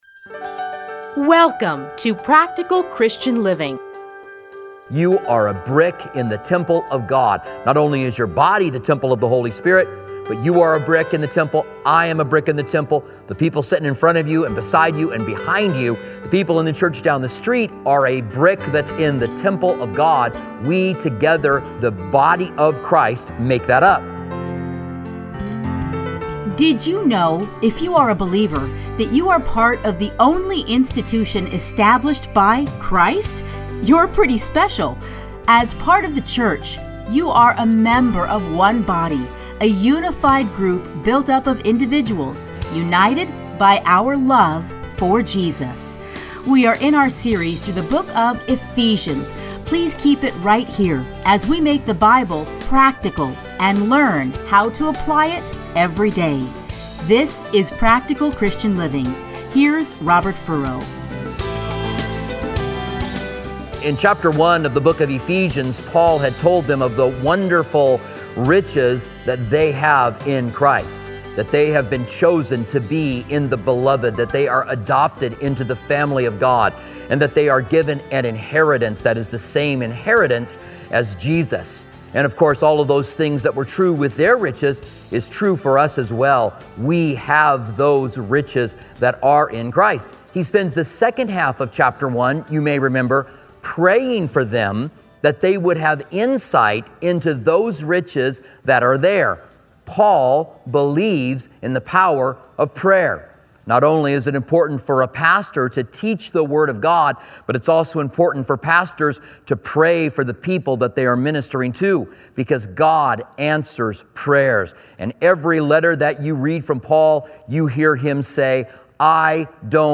30-minute radio programs titled Practical Christian Living